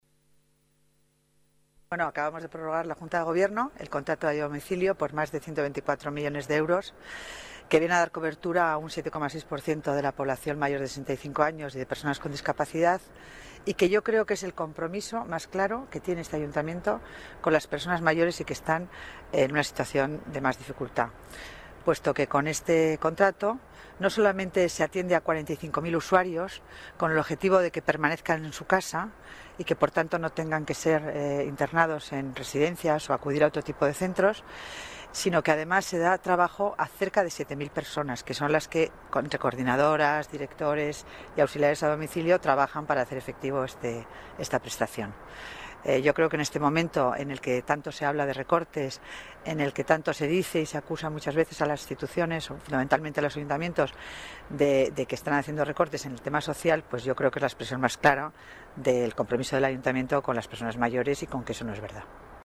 Nueva ventana:Declaraciones delegada Familia y Servicios Sociales, Concepción Dancausa: ayuda a domicilio para mayores, discapacitados y menores